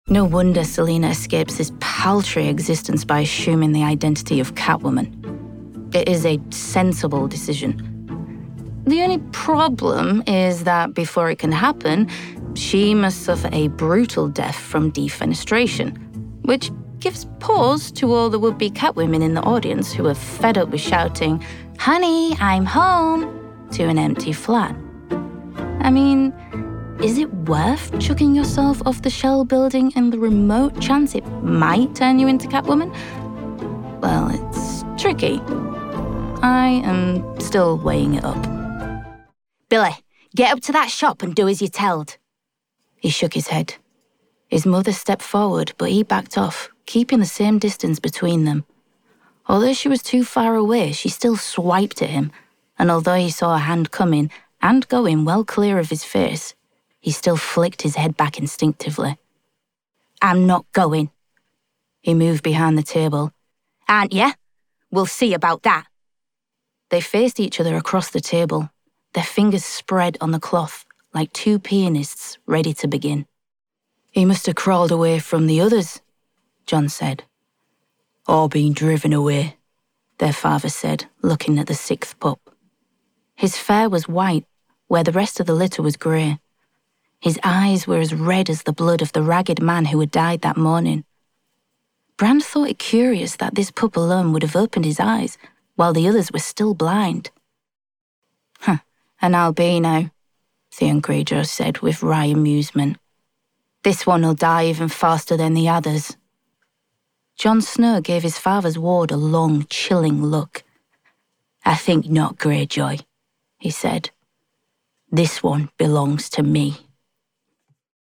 Audiobook Showreel
Female
Hull - Yorkshire
Northern